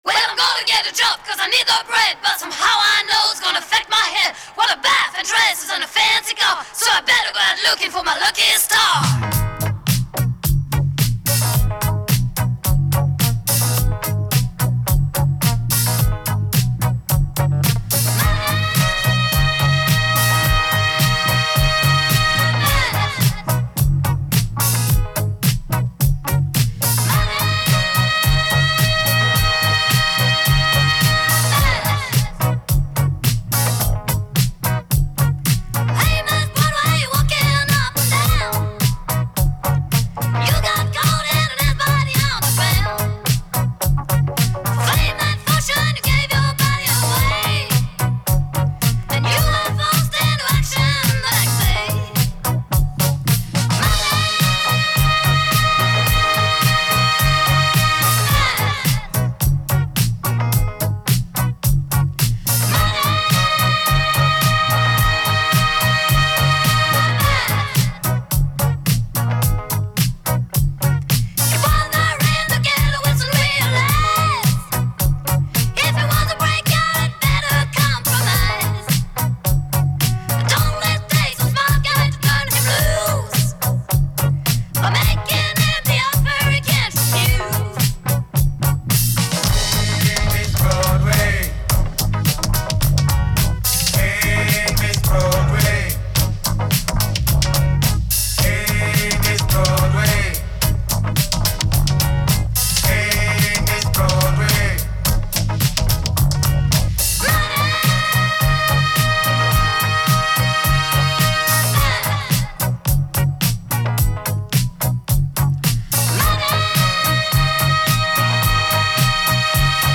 Жанры: Евродиско, Поп-музыка